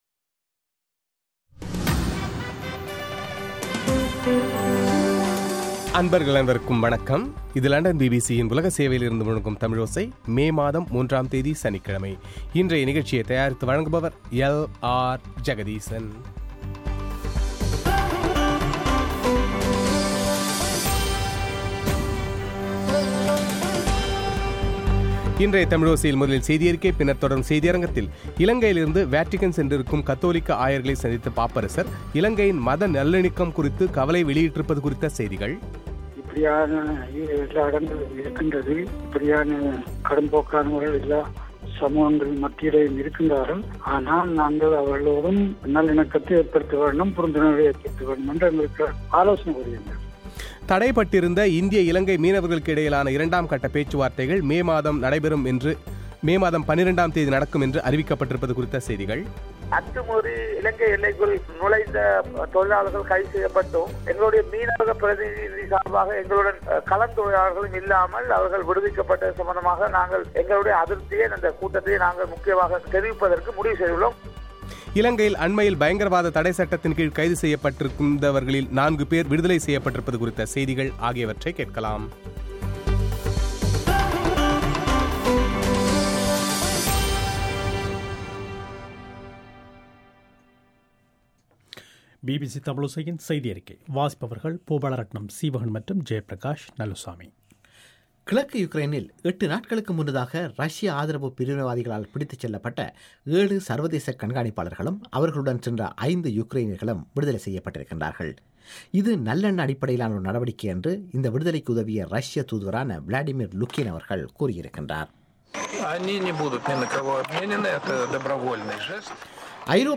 இலங்கையில் தனியொரு மத அடையாளத்தை மையப்படுத்தி-போலியாக தேசிய ஒற்றுமை என்கின்ற கருத்தை ஊக்குவித்துவரும் மத கடும்போக்குவாதிகளால் குழப்பங்களும் பல்வேறு அச்சுறுத்தல் மற்றும் வன்முறைகளும் நடந்துவருவதாக இலங்கையிலிருந்து வத்திக்கான் சென்றுள்ள கத்தோலிக்க ஆயர்மாரிடம் பாப்பரசர் பிரான்சிஸ் கவலை வெளியிட்டிருப்பது குறித்து வத்திக்கானில் தற்போது இருக்கும் திருகோணமலை மறைமாவட்ட ஆயர் கிங்ஸ்லி சுவாம்பிள்ளை பிபிசி தமிழோசைக்கு வழங்கிய சிறப்புச்செவ்வி;